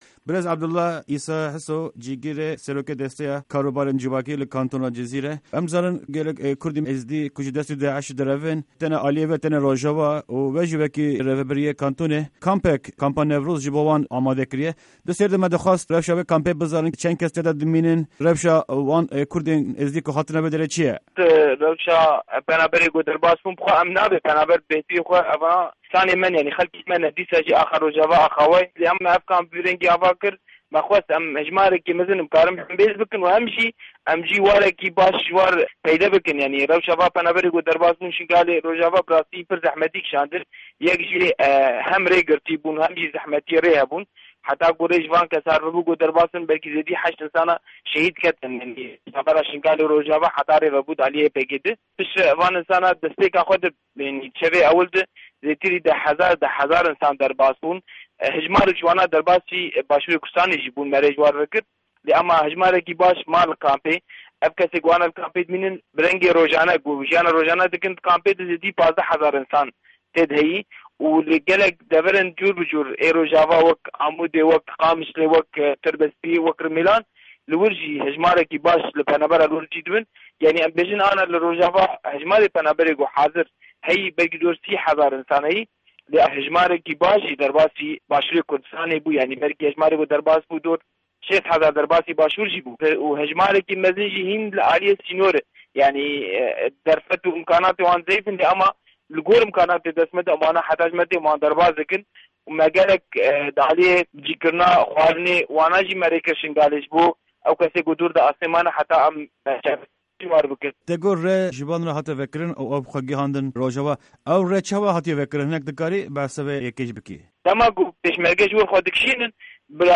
Di hevpeyvîna Dengê Amerîka de Abdullah Îsa Huso, Cîgirê Serokê Karên Civakî dibêje niha 30 hezar Kurdên Êzîdî li Rojava hene û 15 hezar ji wan li Kampa Newroz dimînin.